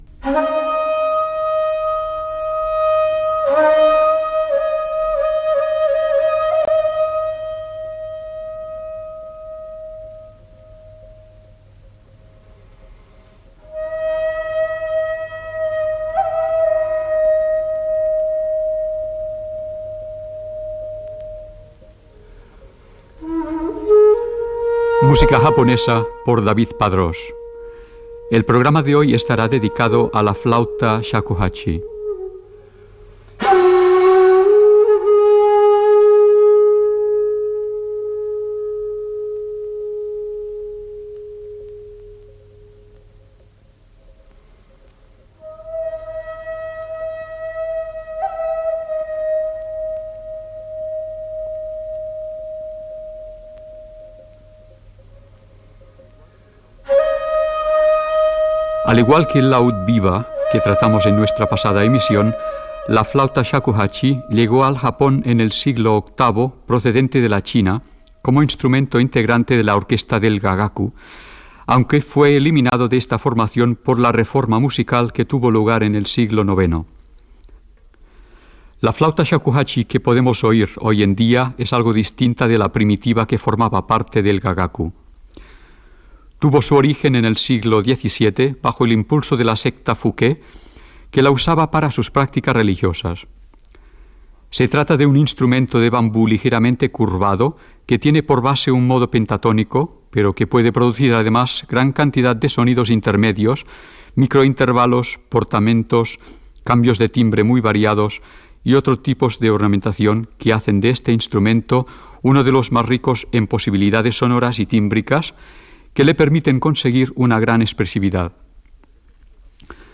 Presentació de l'espai dedicat a la flauta japonesa shakuhachi i un parell de temes musicals
Musical